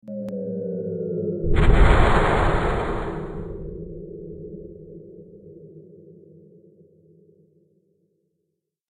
دانلود صدای بمب 7 از ساعد نیوز با لینک مستقیم و کیفیت بالا
جلوه های صوتی